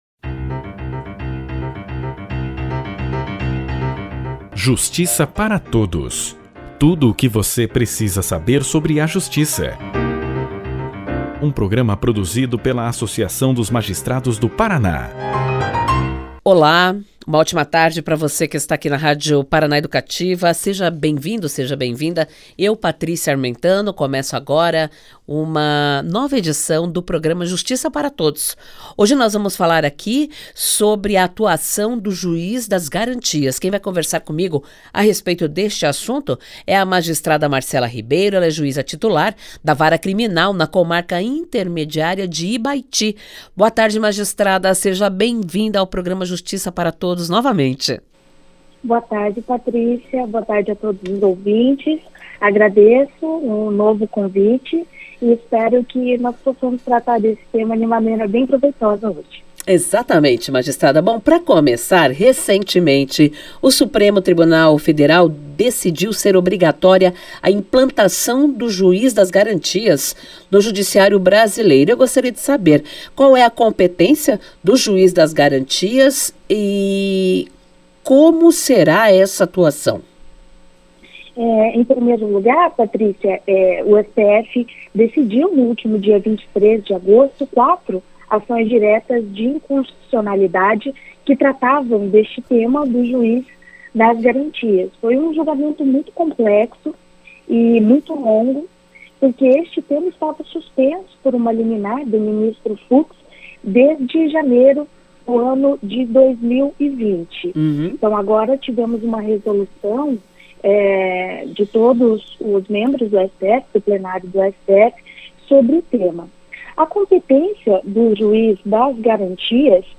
Entrevistada pelo Programa de Rádio da AMAPAR, Justiça Para Todos, a Juíza Titular da Vara Criminal de Ibaiti, Marcella Ribeiro, falou aos ouvintes sobre o instituto do Juiz das Garantias. A matéria foi apreciada recentemente pelo STF, que decidiu pela constitucionalidade do instituto.